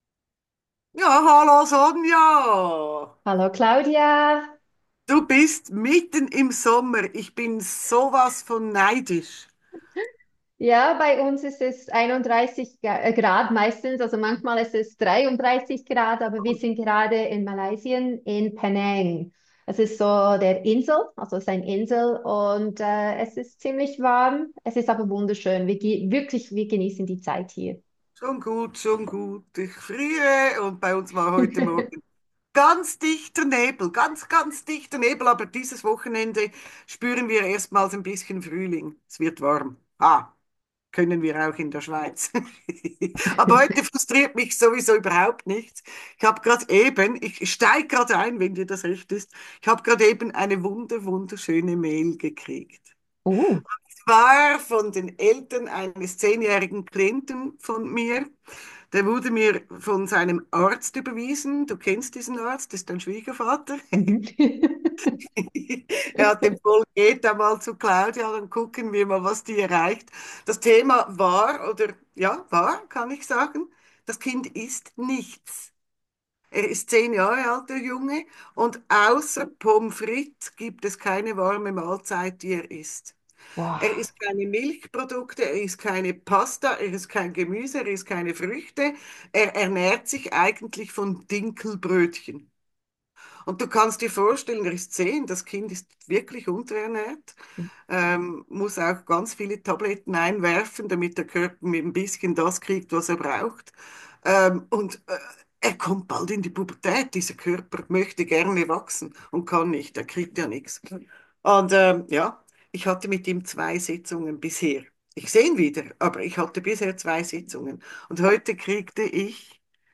Der spontane Wochentalk